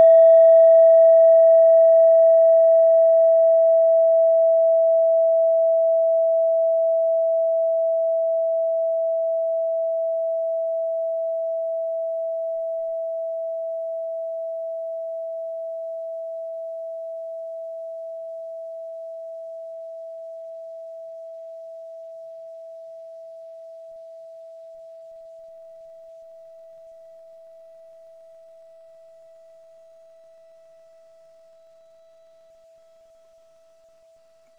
Kleine Klangschale Nr.3 Bengalen
Planetentonschale: Hopiton
Sie ist neu und wurde gezielt nach altem 7-Metalle-Rezept in Handarbeit gezogen und gehämmert.
Hörprobe der Klangschale
(Ermittelt mit dem Minifilzklöppel)
Klangschalen-Gewicht: 250g
Klangschalen-Öffnung: 10,5cm
Die Frequenz des Hopitons liegt bei 164,8 Hz und dessen tieferen und höheren Oktaven. In unserer Tonleiter liegt sie beim "E".